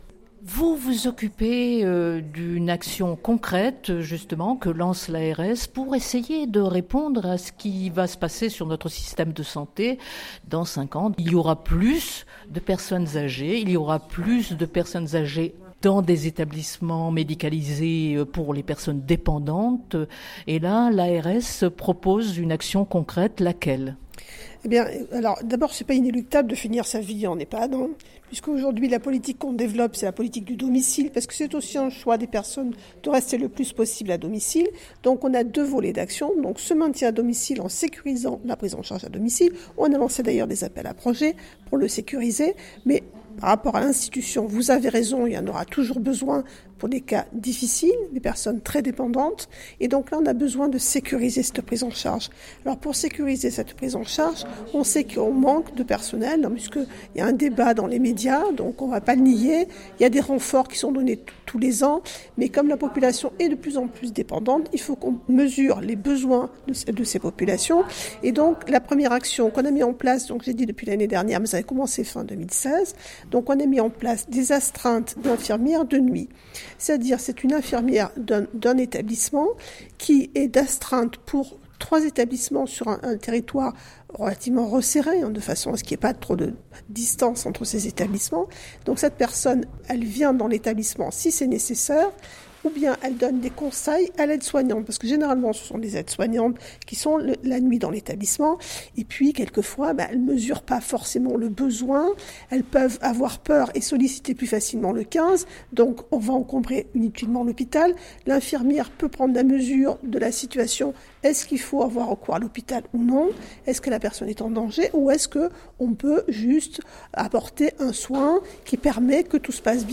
Entretien. claude_d_harcourt_dir_general_de_l_ars_paca_9_10_2018-2.mp3 son_copie_petit-290.jpgA titre d’exemple de sa stratégie d’ensemble, l’Agence présente des actions concrètes pour des publics précis. En voici deux, d’abord pour les personnes âgées en Ehpad, Etablissement d’hébergement pour personnes âgées dépendantes: la présence d’infirmier(ère), la nuit sous forme d’astreinte, mutualisée entre 3 Ehpad, proches. Ce dispositif va être prochainement étendu à l’ensemble du département du Var et puis aux autres départements…